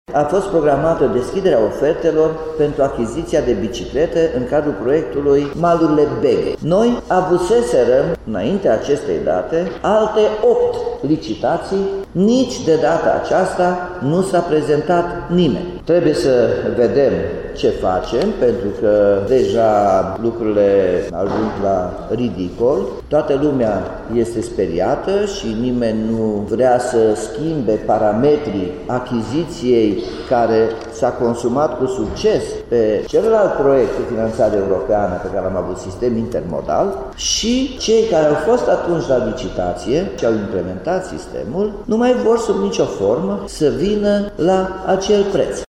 Ultima operaţiune, cea de-a noua, a avut loc ieri dar tot fără succes, spune primarul Nicolae Robu:
02-Nicolae-Robu-biciclete.mp3